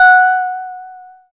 SYN HARPLI03.wav